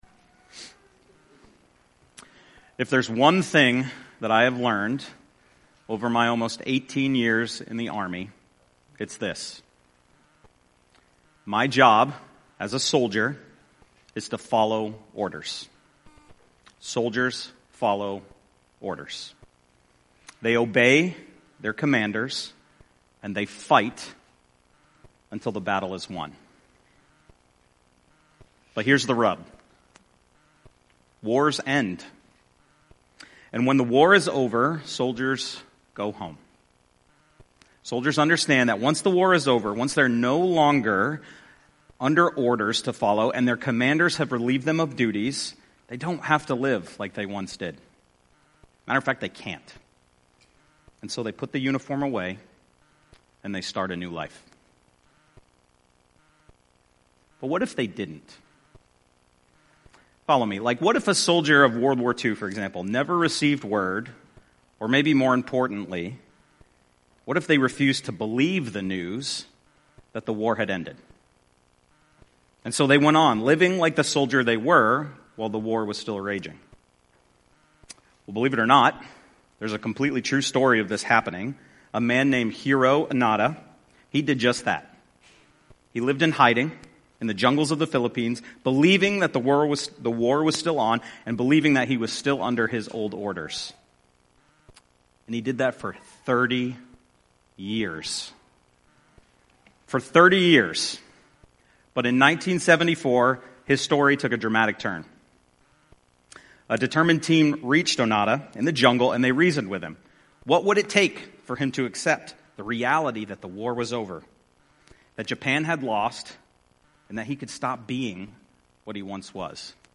Abiding In This Last Hour Redeeming Grace Church (RGC) Sermons podcast